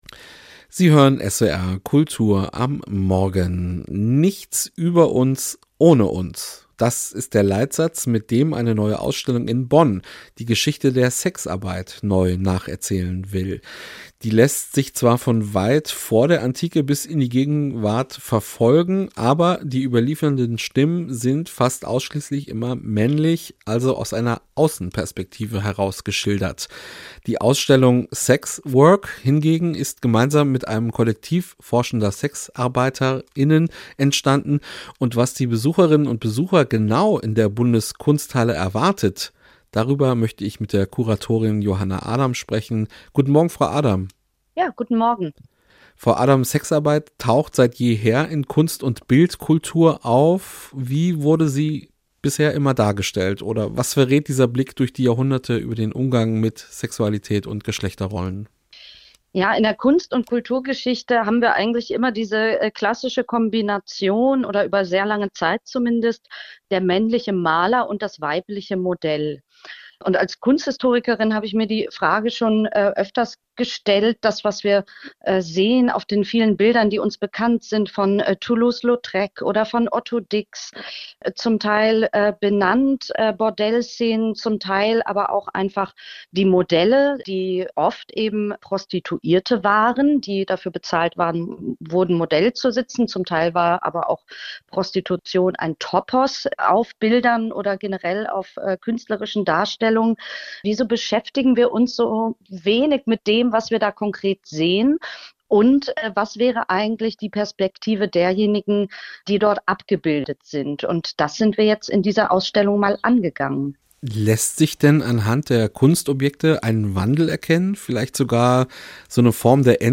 Interview mit